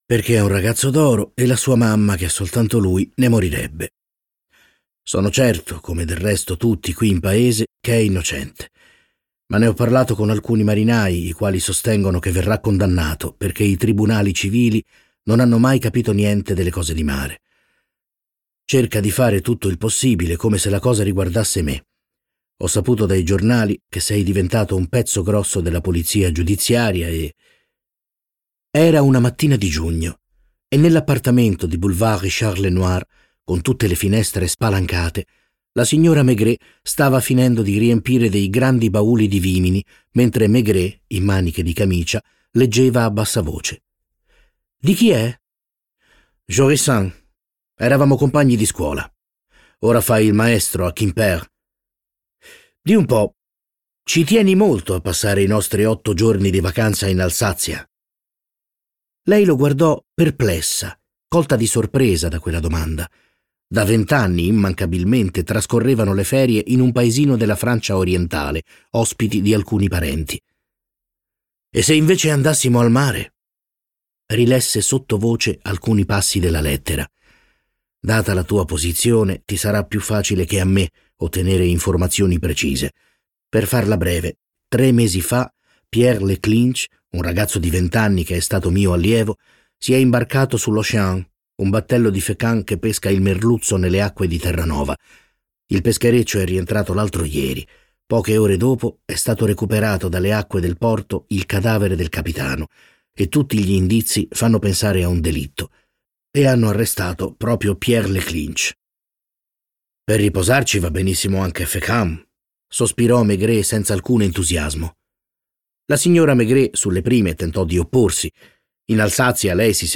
letto da Stefano Fresi
Versione audiolibro integrale